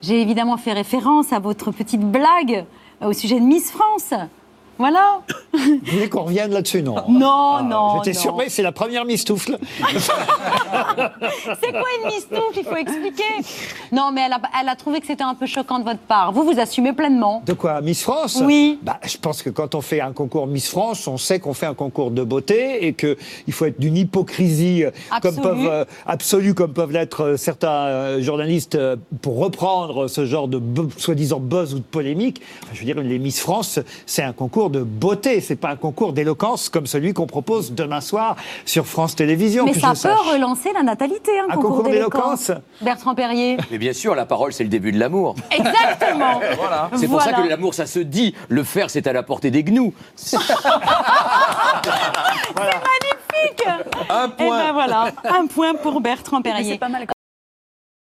Hier Laurent Ruquier était l’invité d’Anne-Elisabeth Lemoine dans son émission « C à vous » sur France 5.